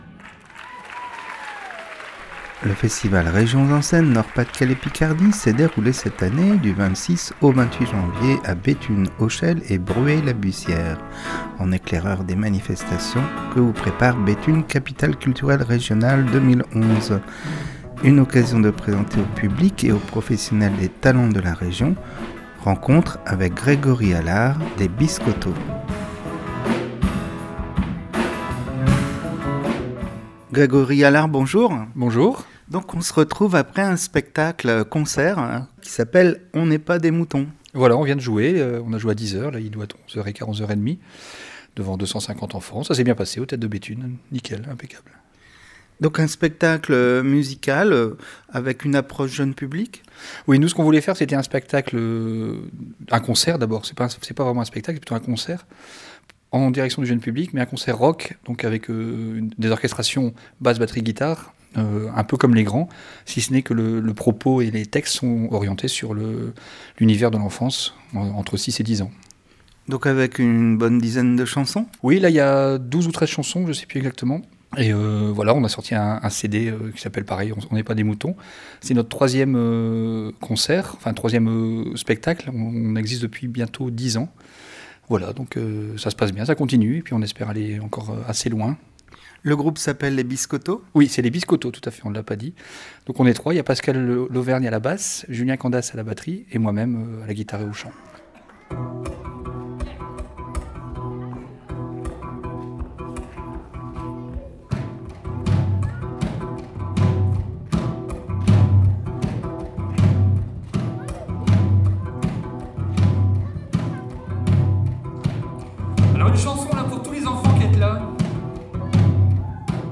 Voici quelques interviews réalisées lors de cette édition